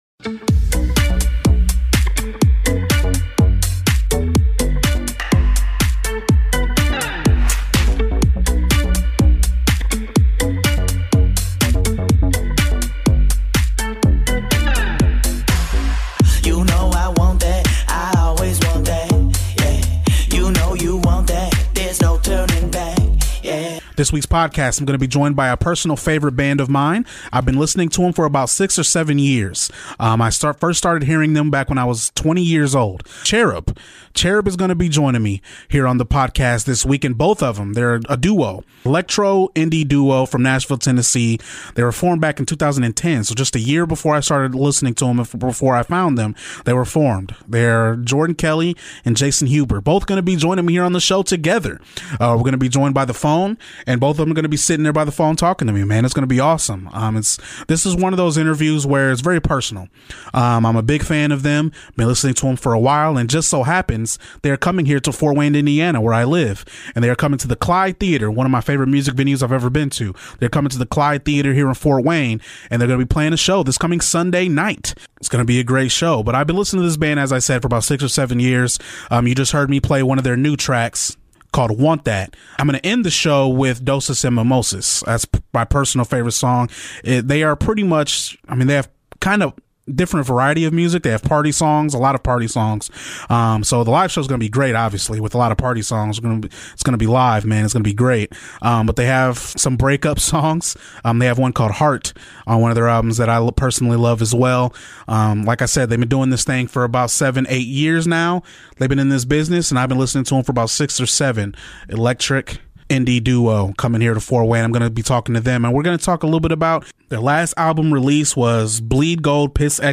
Throwback Interview: Cherub joins the show to talk about their new single, "All In," promote their tour, and much more.